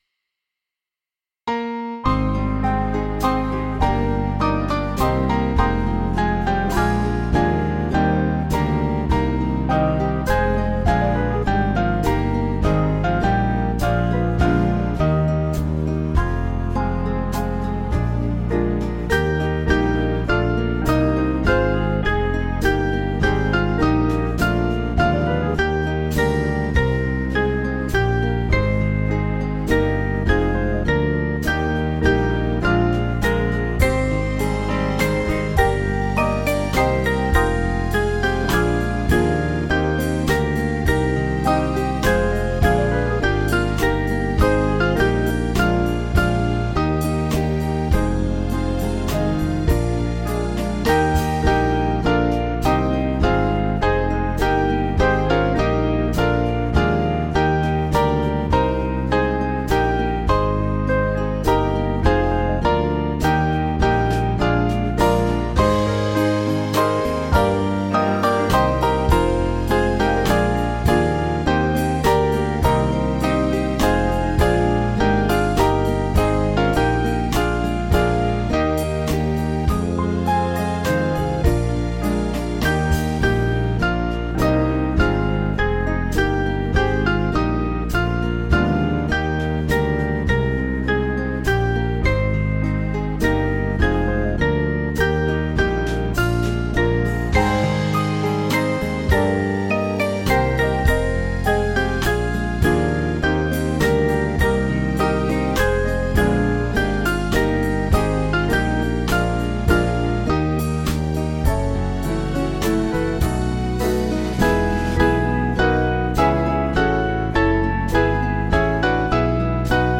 Small Band
(CM)   4/Eb-E